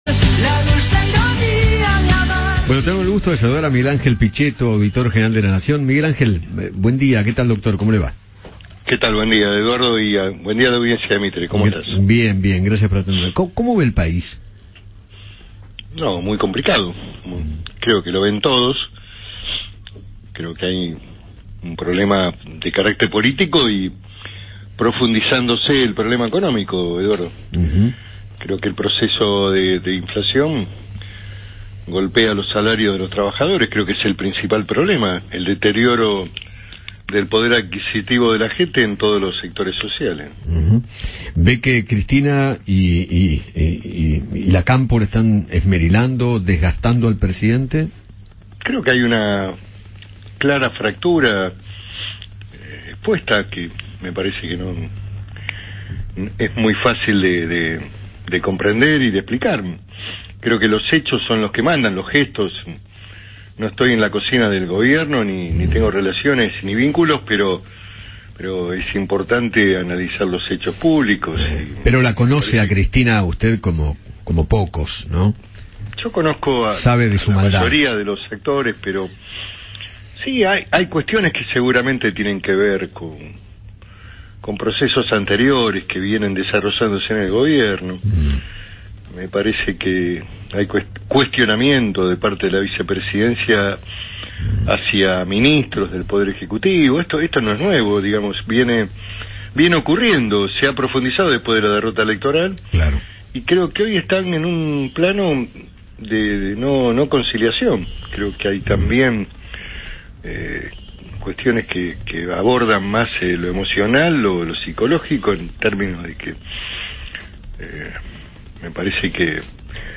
Miguel Ángel Pichetto, Auditor General de la Nación en representación de la Cámara de Diputados, charló con Eduardo Feinmann sobre el presente del país y analizó la coyuntura política.